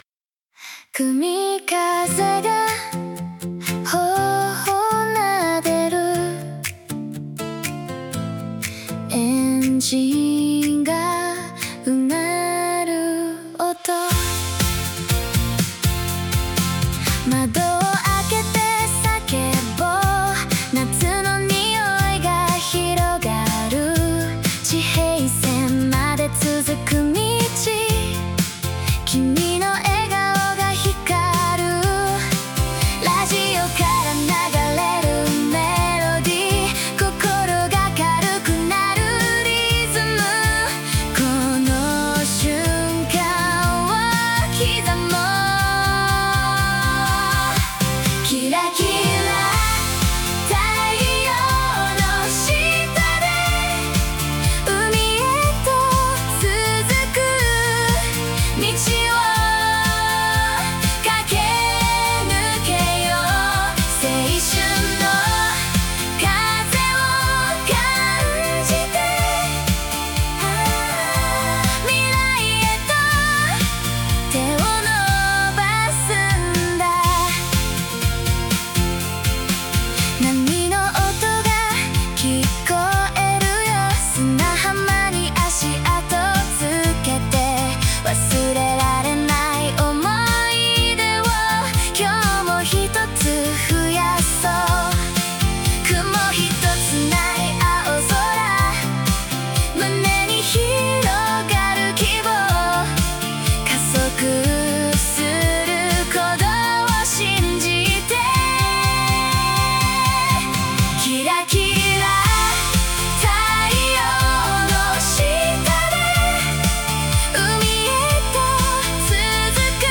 爽やかな夏の青春ポップソング。
• 女性ボーカル、ポップ、明るい雰囲気、軽快なアコースティックギターのストローク、ミッドテンポ（120 BPM）、軽いリバーブがかかったクリアなボーカル、重なり合うハーモニー、夏のビーチのような雰囲気
AIが自動で生成した曲